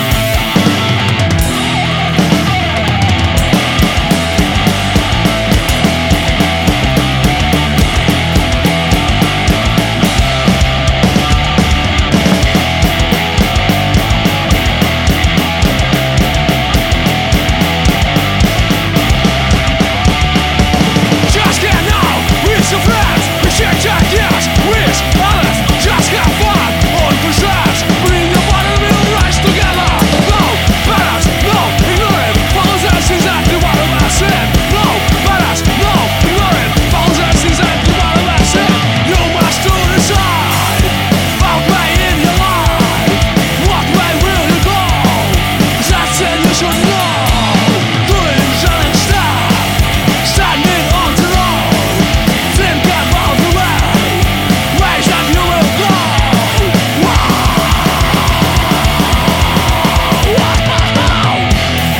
Панк рок, все живое :)